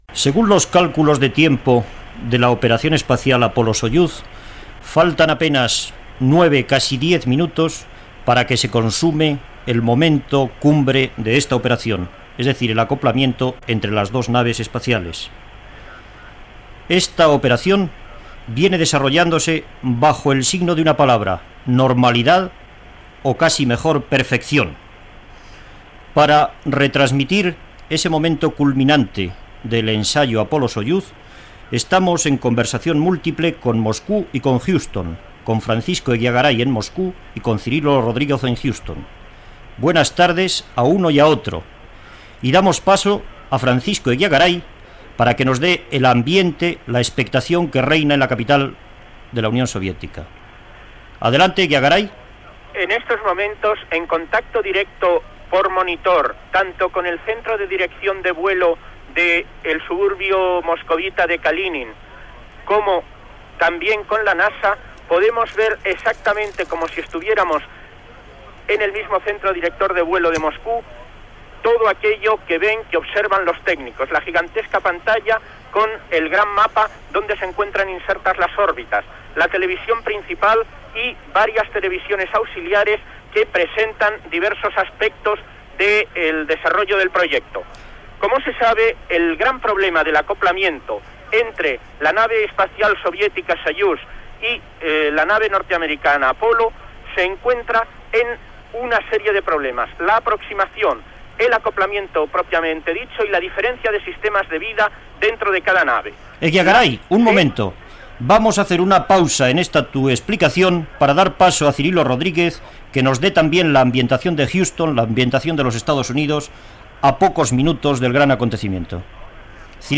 51beaf0dd8e0b711ed8e72138a6237e3df21a62c.mp3 Títol Radio Nacional de España Emissora Radio Nacional de España Barcelona Cadena RNE Titularitat Pública estatal Descripció Connexió múltiplex amb Moscou i Huston per fer la transmissió de l'acoblament de les naus espacials Apolo, dels EE.UU. i Soyuz, de la Unió Soviètica. Gènere radiofònic Informatiu